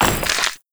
SPLAT_Generic_01_mono.wav